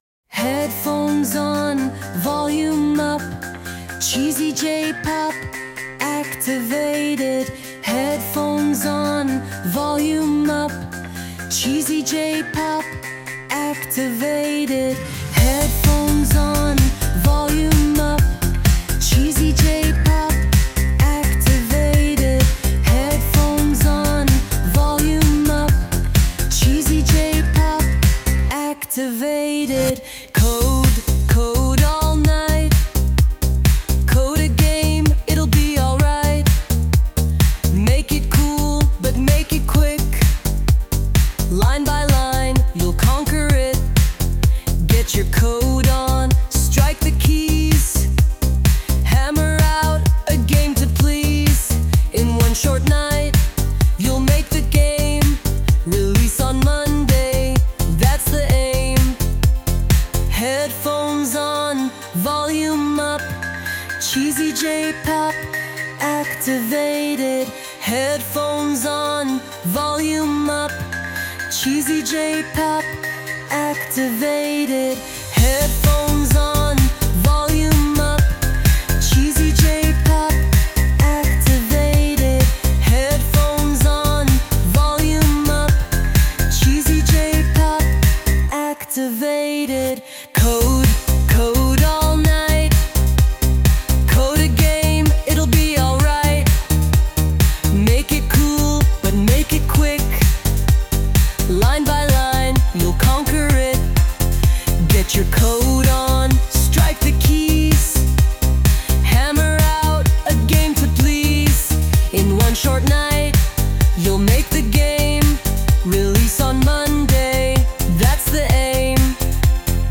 Lyrics : By me
Sung by Suno